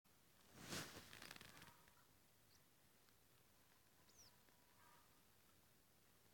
Sarkanrīkles čipste, Anthus cervinus
Ziņotāja saglabāts vietas nosaukumsBurtnieks, Rūdekas